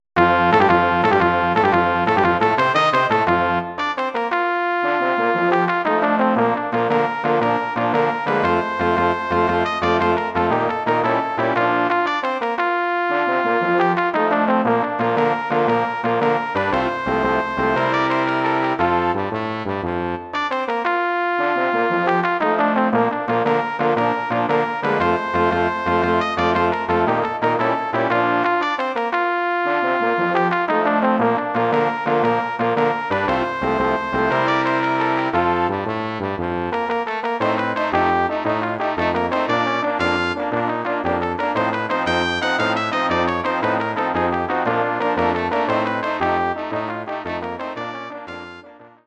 Marsch (1882)
Bearbeitung für Blechbläserquintett
Besetzung: 2 Trompeten, Horn, Posaune, Tuba
arrangement for brass quintet
Instrumentation: 2 trumpets, horn, trombone, tuba